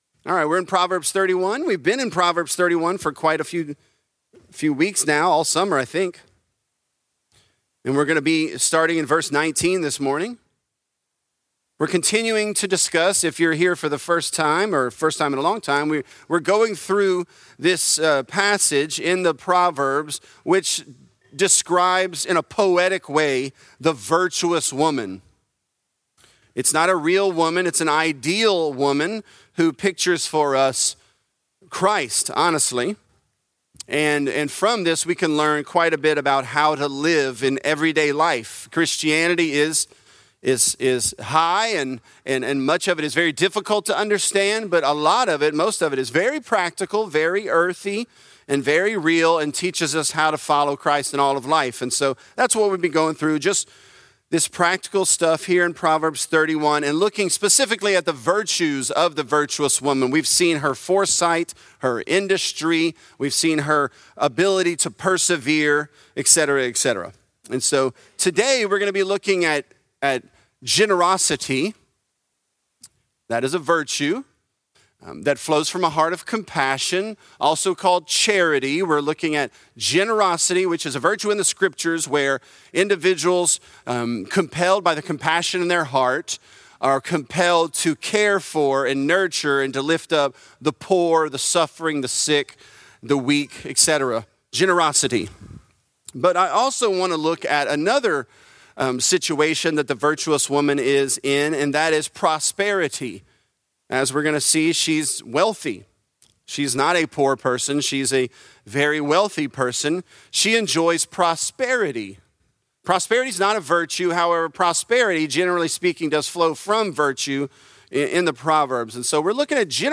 Virtuous: She Opens Her Hands to the Poor | Lafayette - Sermon (Proverbs 31)